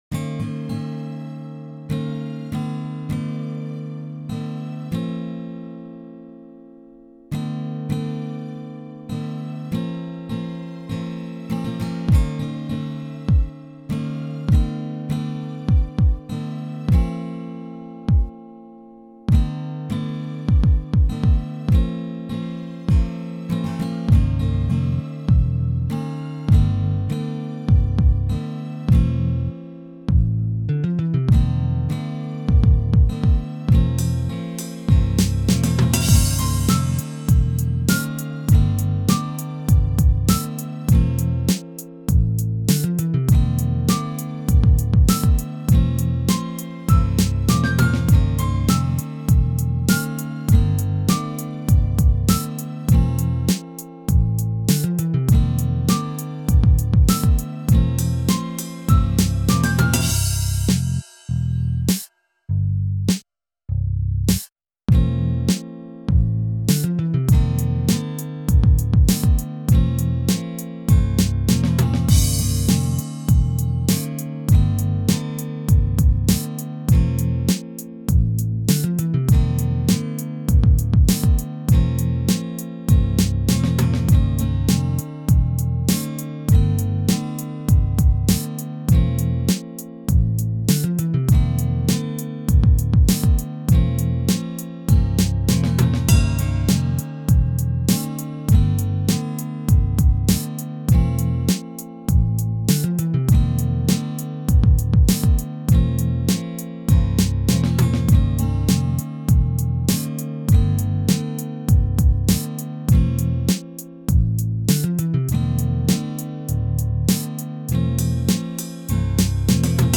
Home > Music > Beats > Medium > Laid Back > Chasing